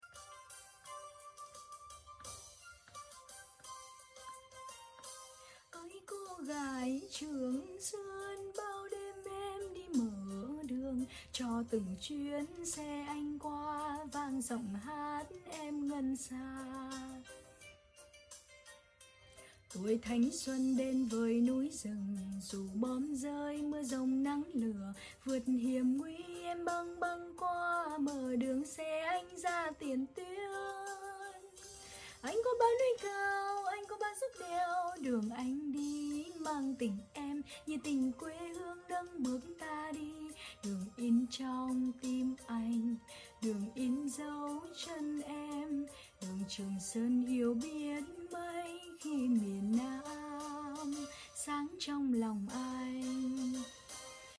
Lâu lâu ẻm lại ngoi lên hát zuii zuii cho mí bà iuu cùng sở thích nghe đâyyy